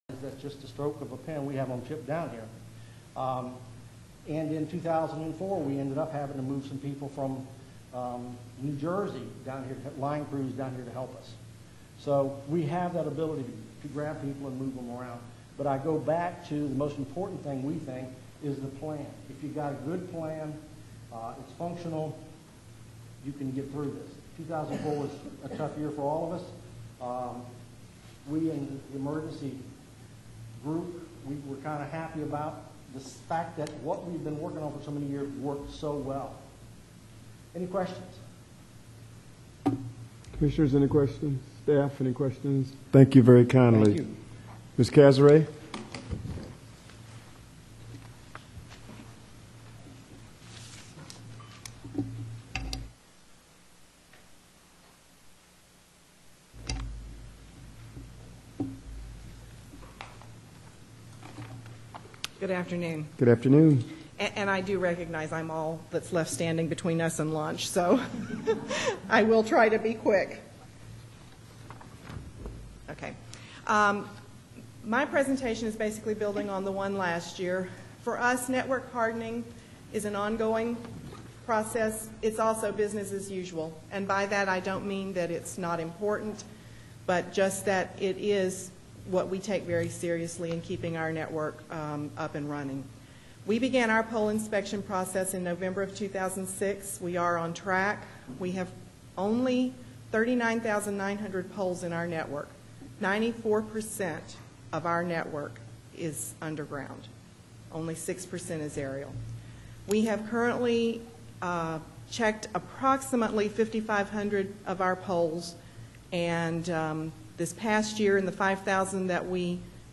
Hurricane Workshop Audio (afternoon).wma